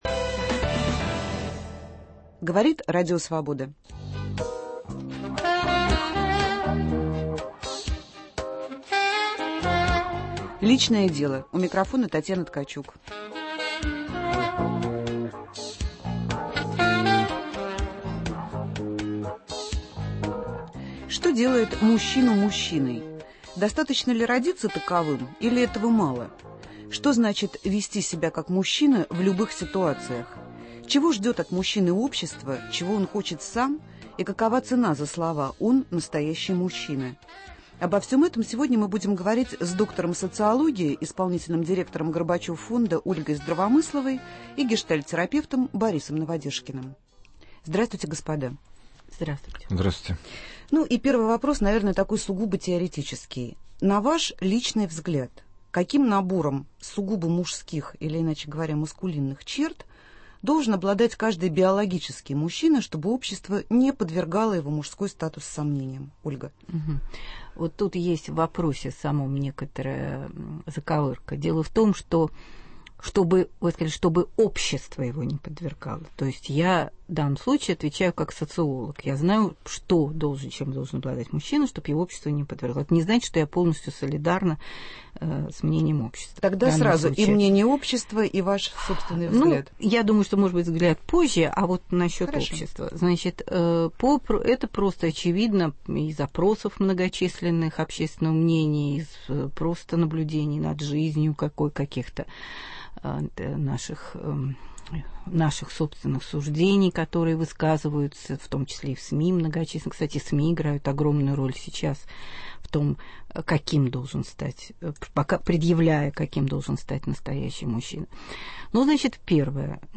Что, по-вашему, делает мужчину мужчиной? 23 февраля в прямом эфире мы будем говорить о том, что делает мужчину мужчиной - с точки зрения общественных ожиданий, и с точки зрения самого мужчины. Как меняются идеалы мужественности?